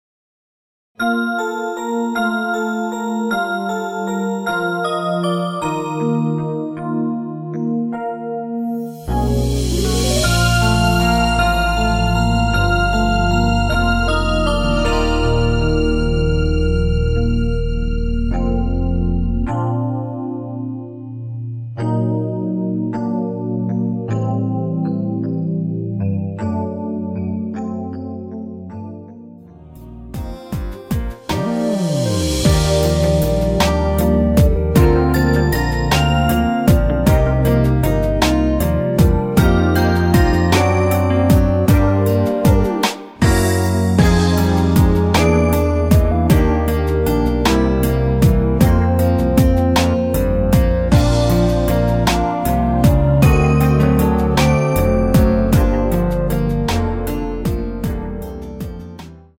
MR입니다
원곡의 보컬 목소리를 MR에 약하게 넣어서 제작한 MR이며